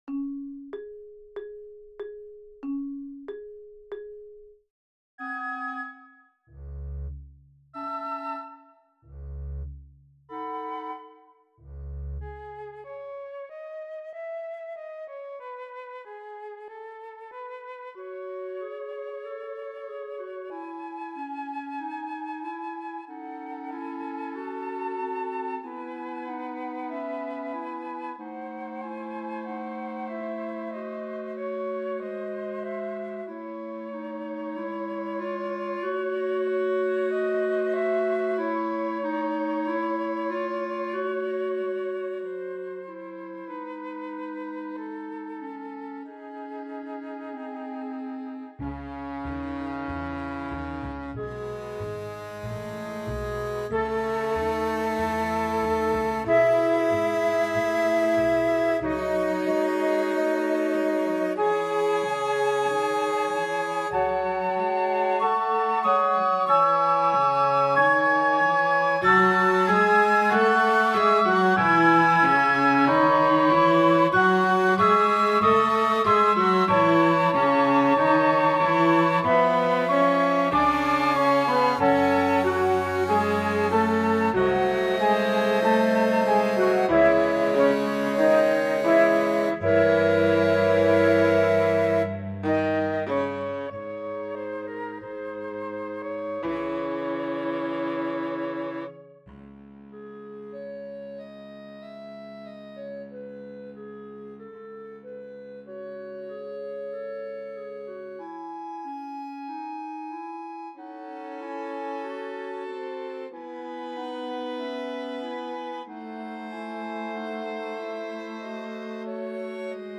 PlayAlong 1